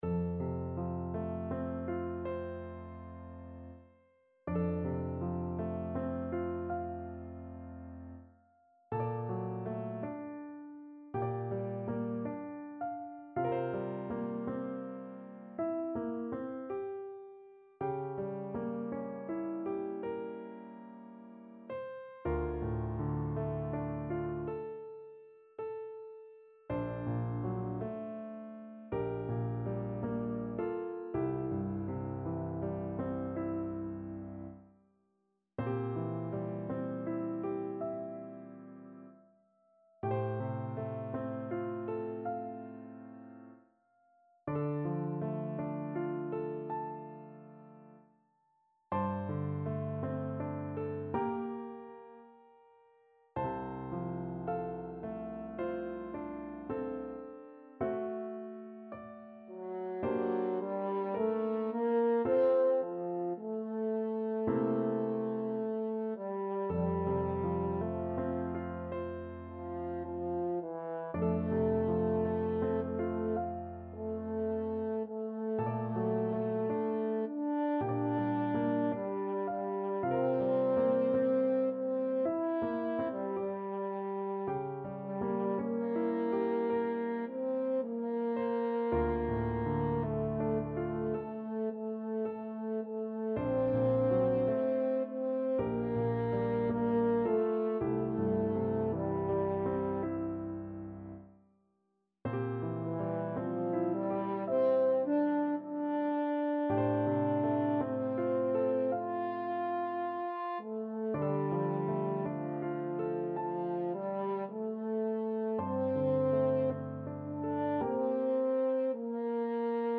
4/4 (View more 4/4 Music)
~ = 54 Langsam
Classical (View more Classical French Horn Music)